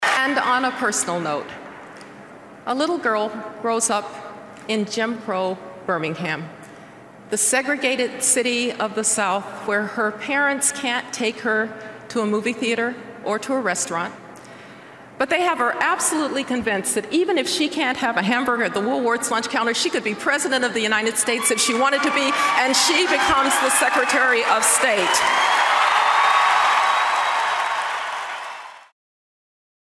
She made that case with extreme eloquence during her keynote address at the 2012 Republican National Convention.